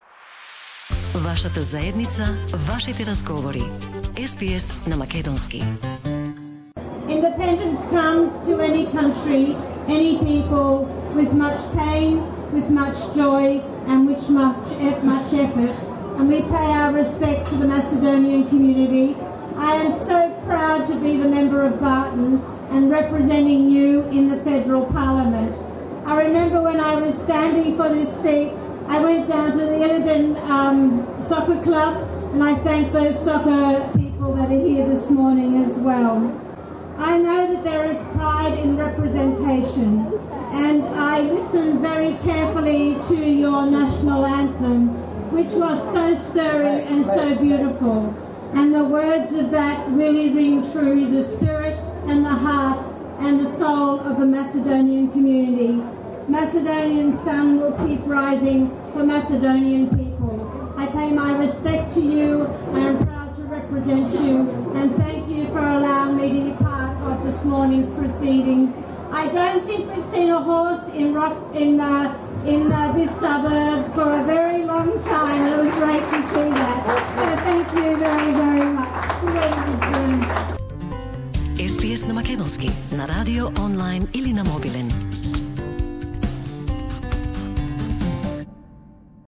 Hon Linda Burney MP, Labor Member for Barton greets community in Rockdale at the Macedonian Folk Festival in honour of the Independence Day of the Republic of Macedonia